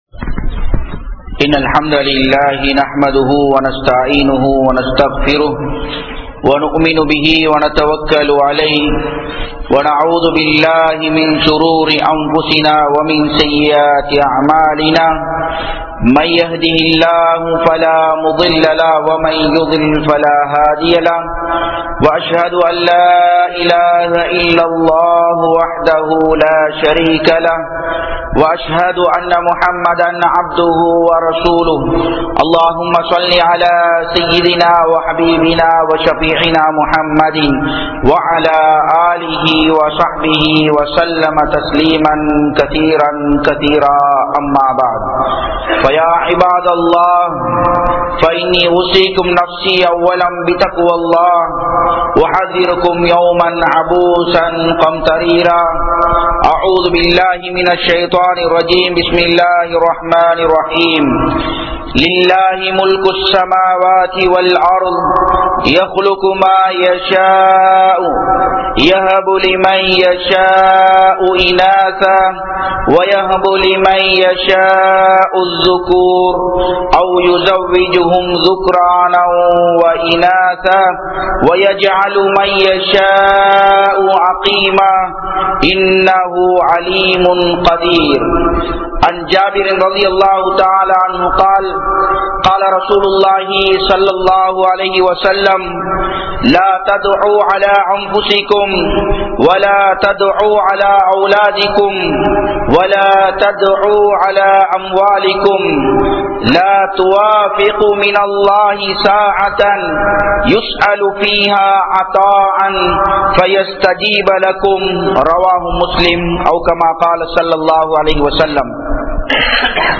Allah`vin Arutkodaihal (அல்லாஹ்வின் அருட்கொடைகள்) | Audio Bayans | All Ceylon Muslim Youth Community | Addalaichenai
Japan, Nagoya Port Jumua Masjidh